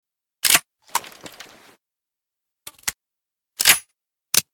bm16_reload_l.ogg